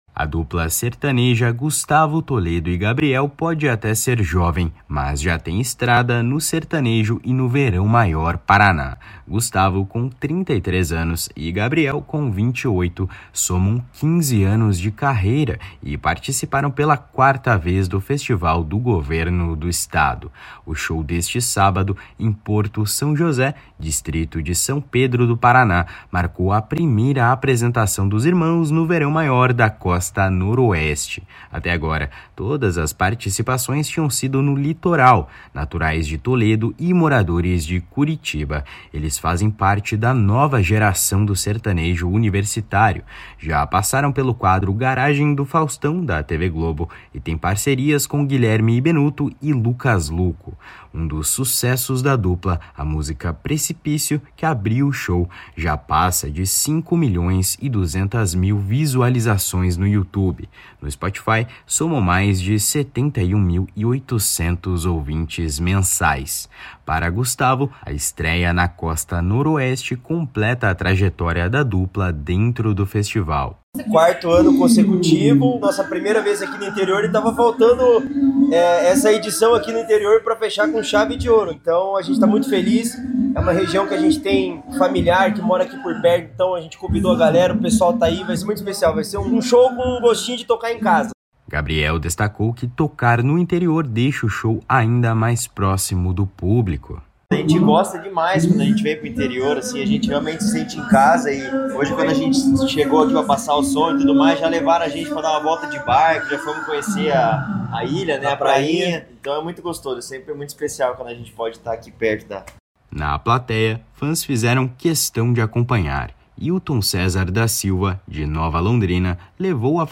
O show deste sábado, em Porto São José, distrito de São Pedro do Paraná, marcou a primeira apresentação dos irmãos no Verão Maior da Costa Noroeste.